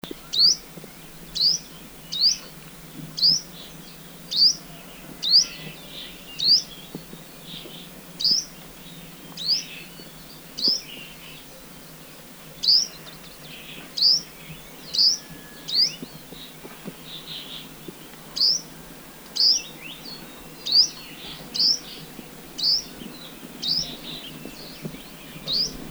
Souimanga à dos vert ( Cinnyris jugularis ) ssp rhizophorae
Cris enregistrés le 07 avril 2012, en Chine, province du Guangxi, à Longfei près de la ville de Beishan.
Cris de Souimanga à dos vert : écouter ici